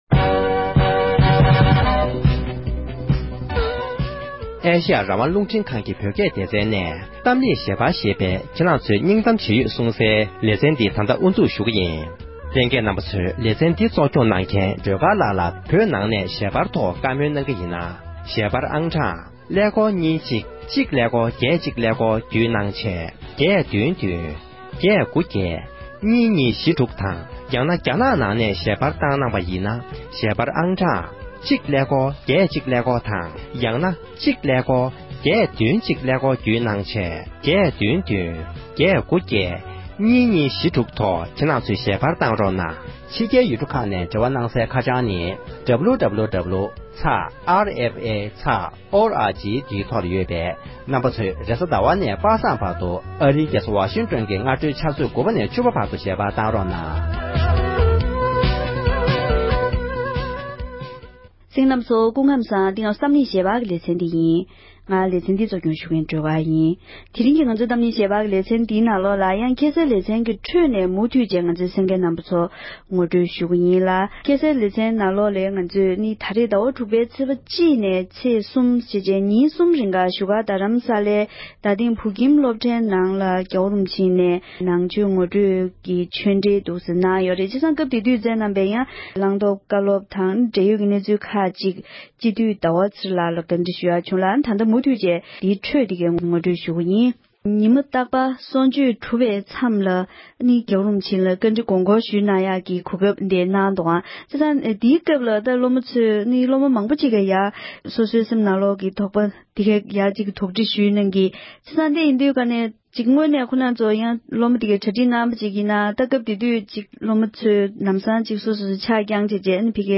འབྲེལ་ཡོད་མི་སྣར་བཀའ་འདྲི་ཞུས་པའི་དུམ་བུ་གསུམ་པ་འདིར་གསན་རོགས་ཞུ༎